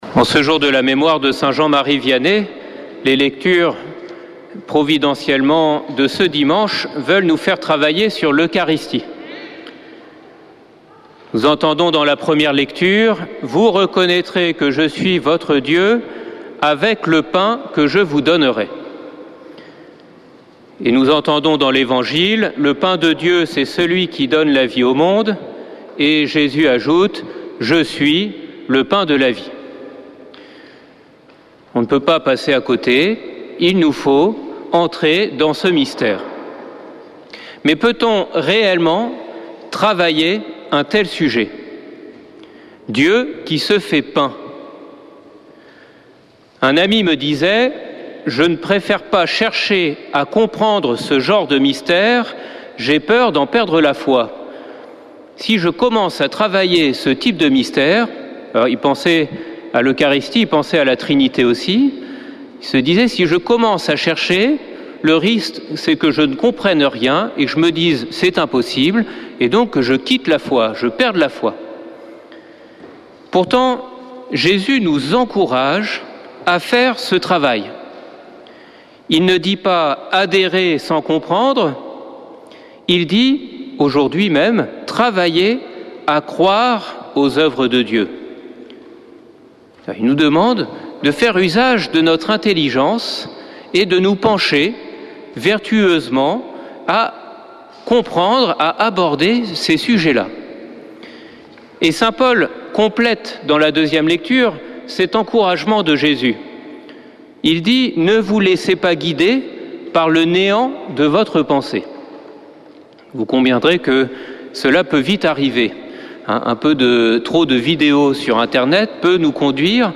dimanche 4 août 2024 Messe depuis le couvent des Dominicains de Toulouse Durée 01 h 30 min
Homélie du 4 août